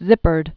(zĭpərd)